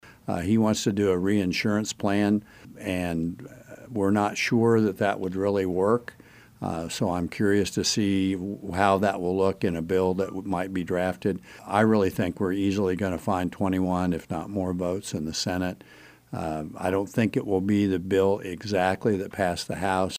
Speaking on KMAN’s In Focus Monday, Sen. Hawk said Gov. Laura Kelly and Sen. Jim Denning have been in negotiations on a bipartisan proposal that may get done as soon as the end of this week.